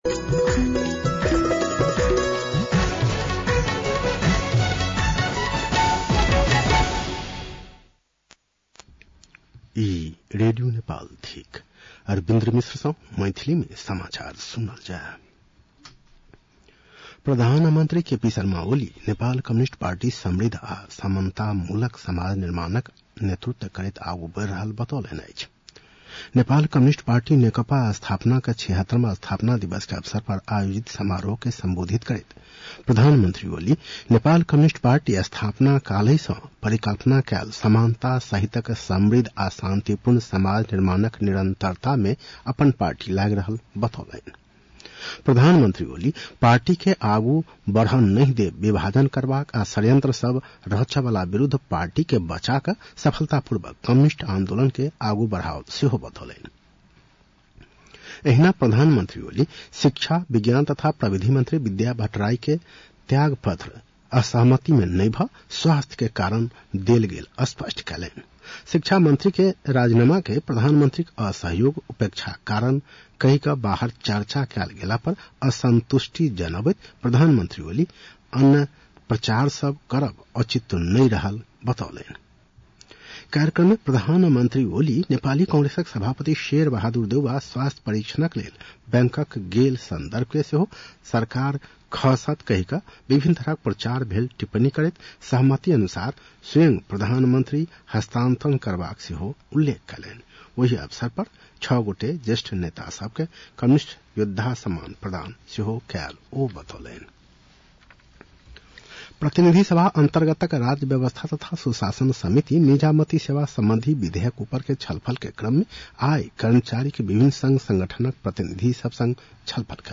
मैथिली भाषामा समाचार : ९ वैशाख , २०८२